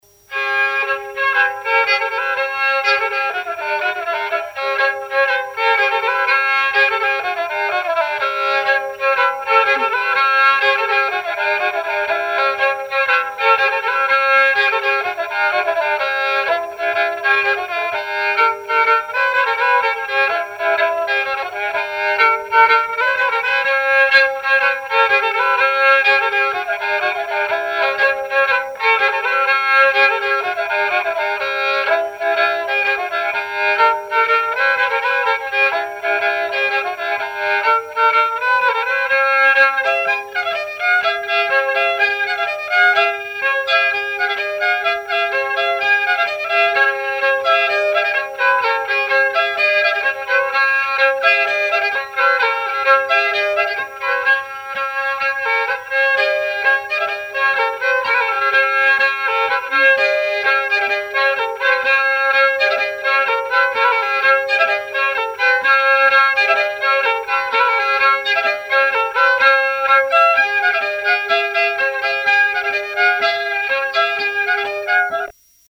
Mémoires et Patrimoines vivants - RaddO est une base de données d'archives iconographiques et sonores.
danse : branle : courante, maraîchine
Pièce musicale inédite